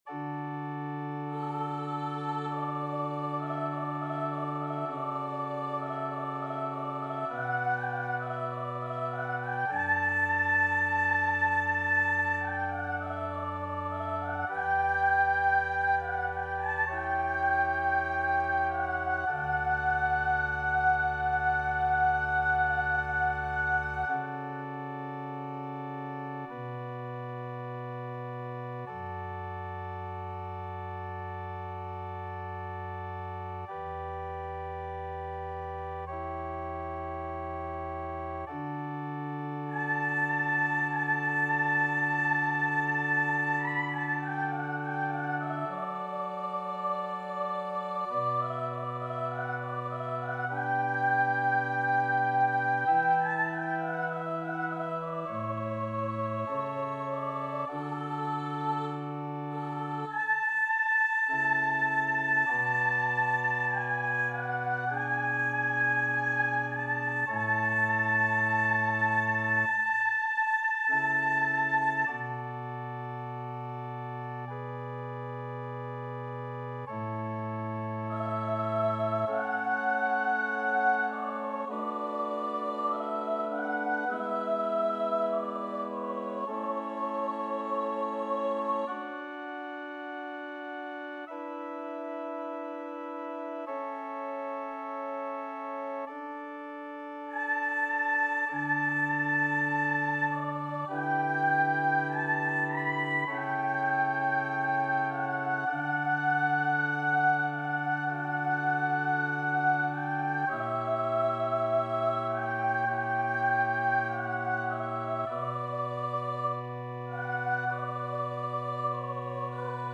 Play (or use space bar on your keyboard) Pause Music Playalong - Piano Accompaniment Playalong Band Accompaniment not yet available transpose reset tempo print settings full screen
D major (Sounding Pitch) E major (Trumpet in Bb) (View more D major Music for Trumpet )
Largo
Classical (View more Classical Trumpet Music)